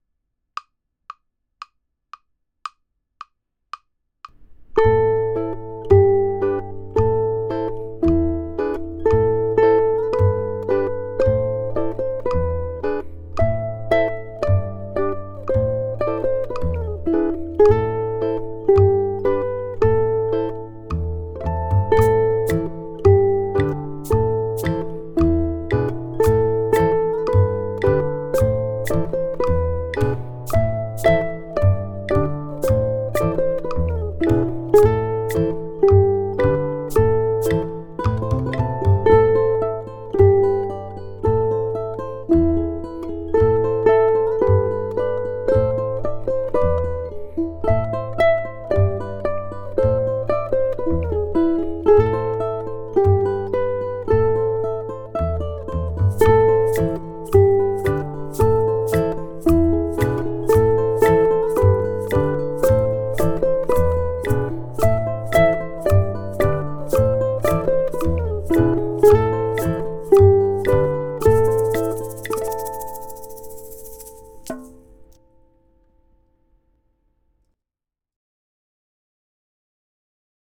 Rose for ʻUkulele Ensemble
In this chapter, you'll take on a five-hundred year old English folk song, Rose.
Rose is arranged as a trio: ʻukulele 1 plays the melody, ʻukulele 2 strums and arpeggiates the chords, and ʻukulele 3 plays a quasi bassline.
At a faster clip, I dare say the melody is swashbuckling.
Aim for a slow allegro of about 120 BPM.
ʻUkulele 2 uses natural harmonics (Har12) in measures 8 and 17.
ʻukulele